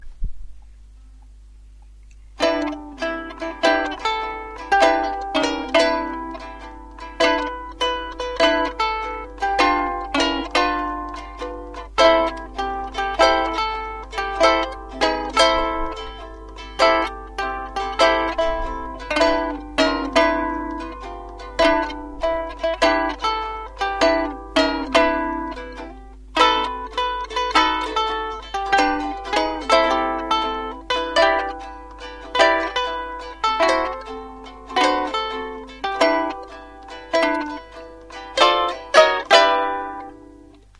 Ukulele im Ensemble, und Panamapapiere, ein bisschen
Vierstimmig, plus eine Ukulele mit den Begleitakkorden, und alle Stimmen kann man natürlich mehrfach besetzen.
Und so hört sich das an, wenn ich das spiele, alle Stimmen, halt nacheinander und noch sehr langsam, und dann übereinandergelegt: